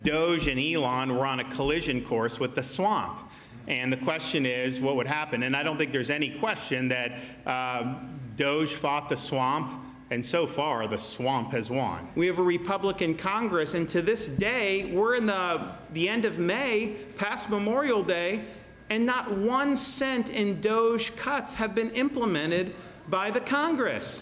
Florida governor Ron DeSantis summed it up: